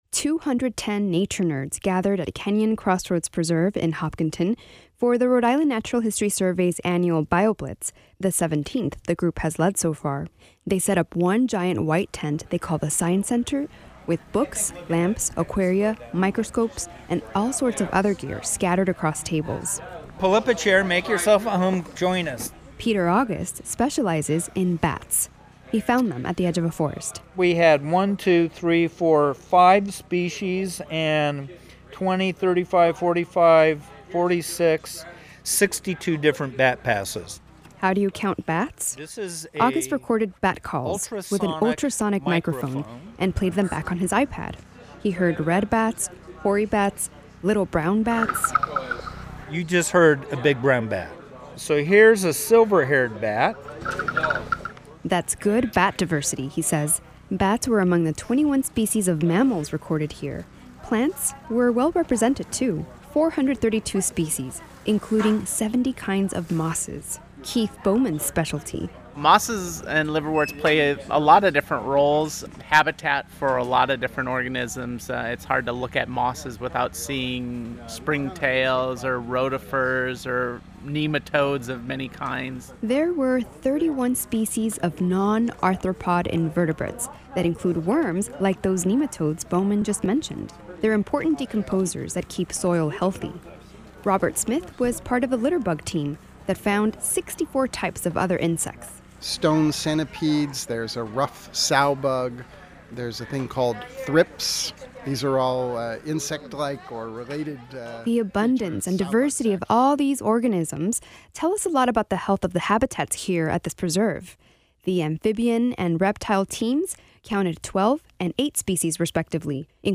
We take you back to summer to give you a sampling of plants and animals they found on a particular parcel of land in Hopkinton.
Two hundred and ten nature nerds gathered at Kenyon Crossroads Preserve in Hopkinton for the Rhode Island Natural History Survey’s annual BioBlitz, the 17th the group has led so far.